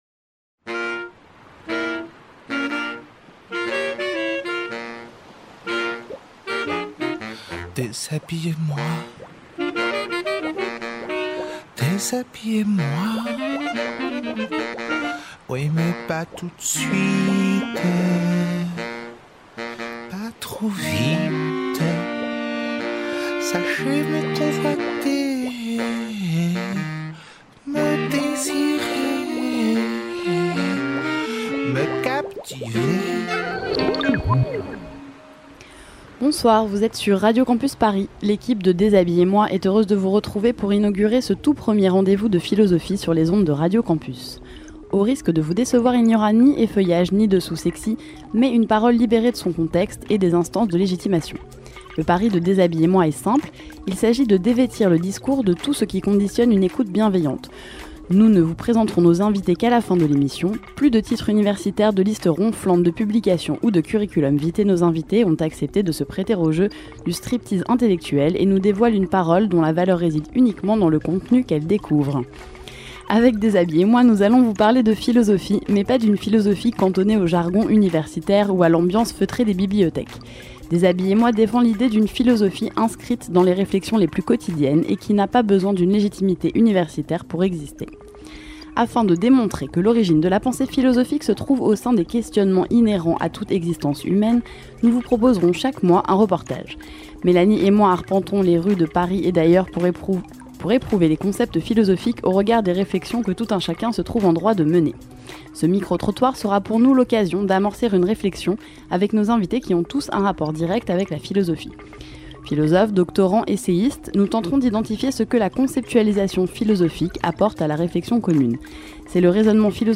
Autant de questions que nous avons posées à des passants ou des amis, sur la plage, dans la rue ou encore dans des cafés.